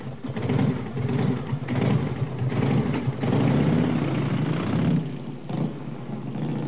startcar.wav